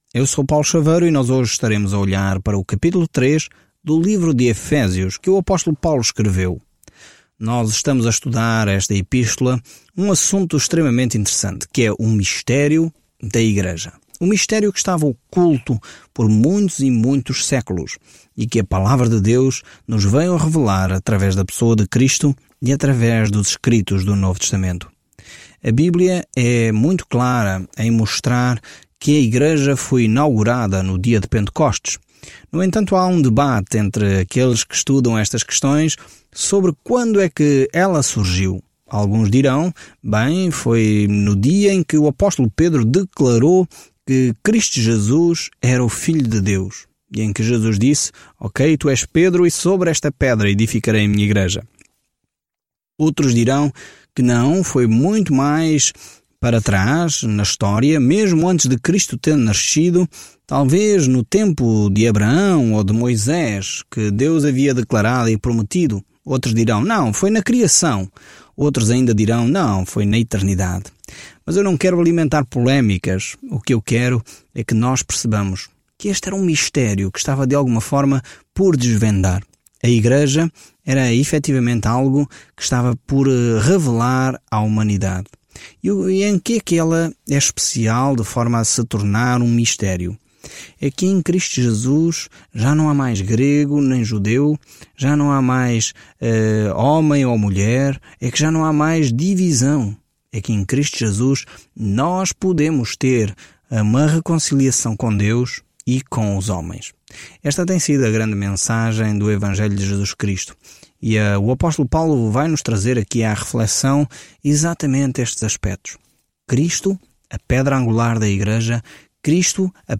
Scripture Ephesians 3:1-13 Day 13 Start this Plan Day 15 About this Plan Das belas alturas do que Deus deseja para seus filhos, a carta aos Efésios explica como andar na graça, na paz e no amor de Deus. Viaje diariamente por Efésios enquanto ouve o estudo em áudio e lê versículos selecionados da palavra de Deus.